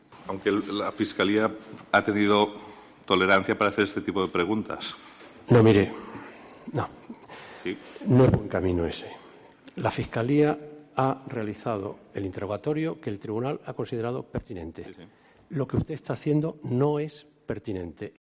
El presidente del Tribunal del Procés llama la atención a uno de los abogados de la defensa